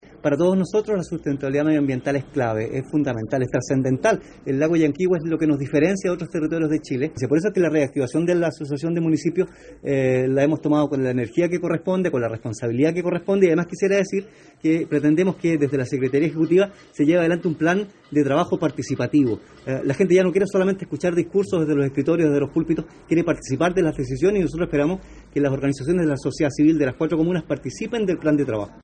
Cesar Huenuqueo, alcalde electo de Frutillar, señaló que la sustentabilidad del Lago Llanquihue es fundamental, por lo que esperan desarrollar un trabajo participativo.